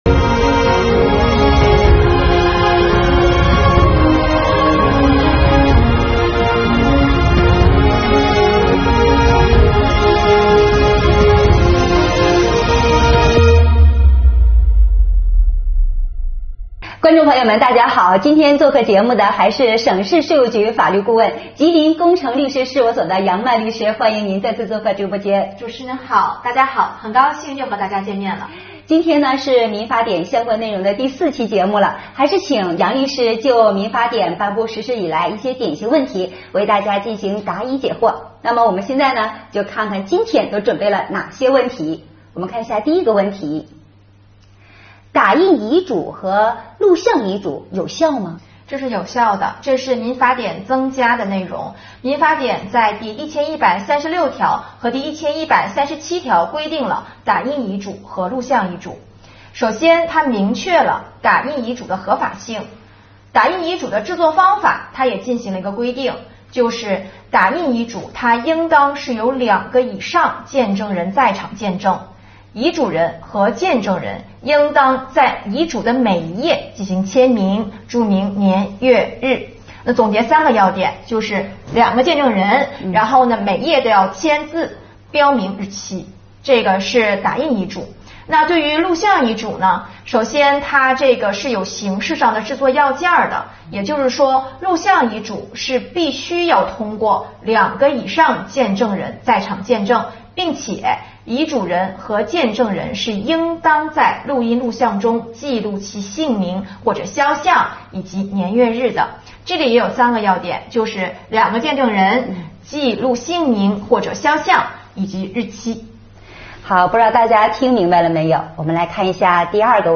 2021年第7期直播回放：《民法典》热点问题解读系列之四